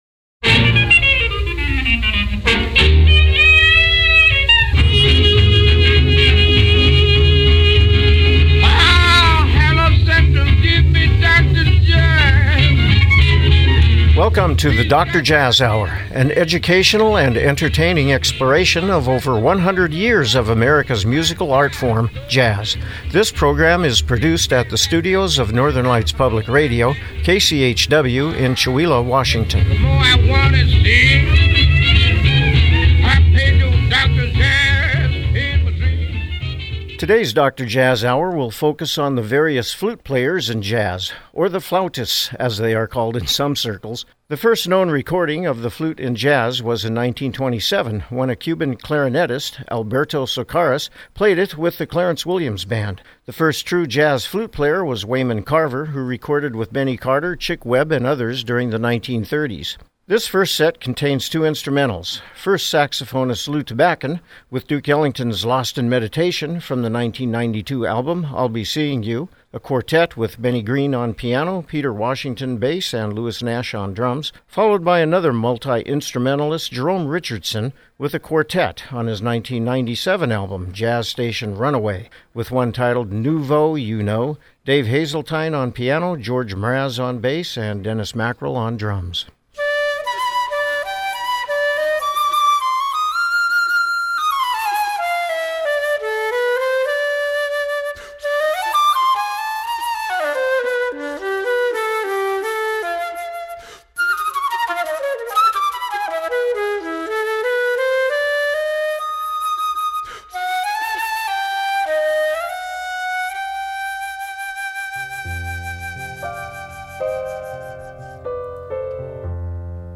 A-Infos Radio Project - DJH 003 - Flute Jazz